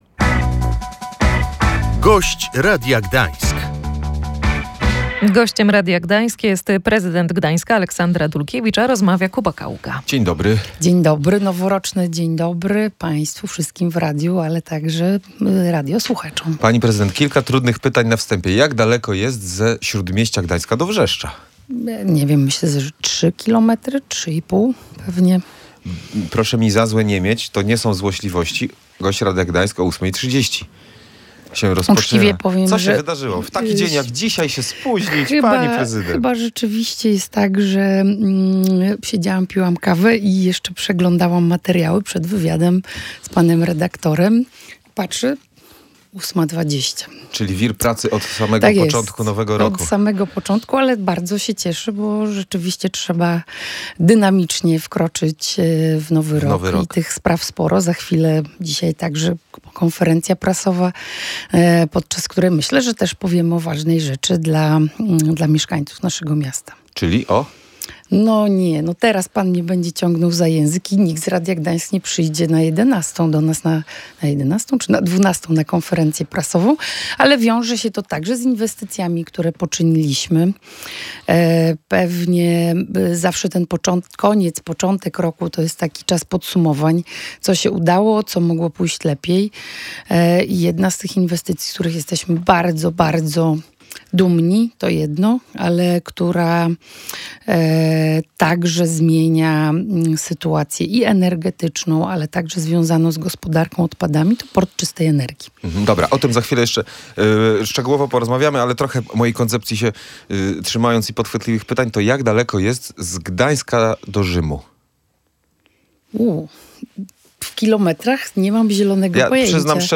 W ostatnich latach Gdańsk jest drugim najlepiej rozwijającym się miastem wśród 20 europejskich. Również w rozpoczętym roku będzie dużo się działo – mówiła w Radiu Gdańsk prezydent miasta Aleksandra Dulkiewicz.